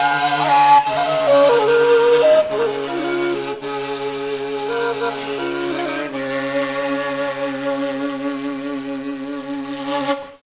TIBETDRON2-R.wav